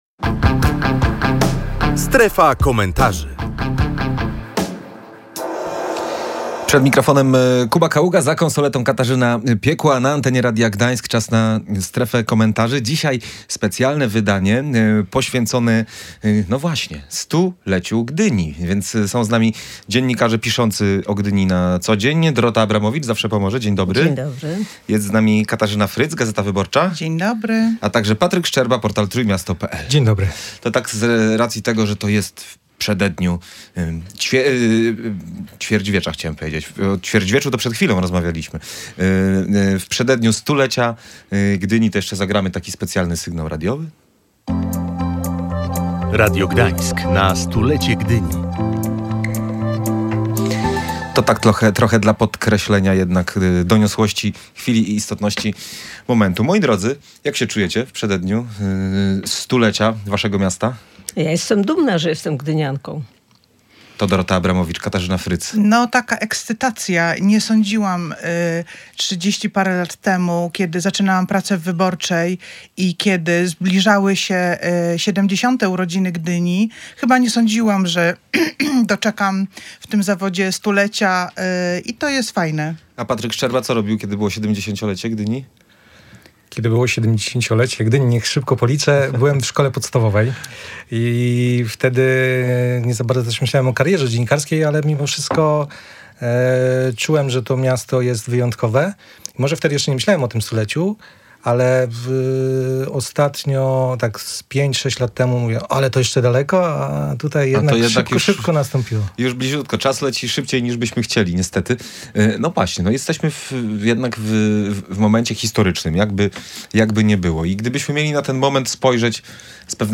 W przededniu jubileuszu w naszym studiu gościliśmy dziennikarzy piszących o Gdyni na co dzień.